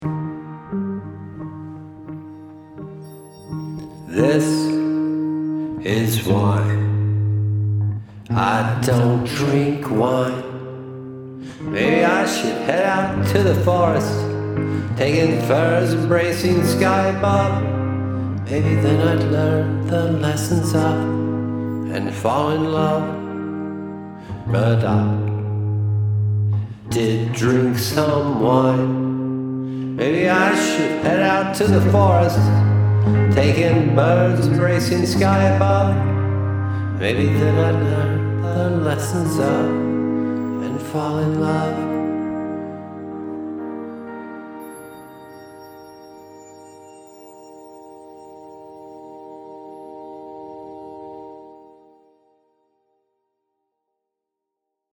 I hope I sound drunk, or hungover, but I am not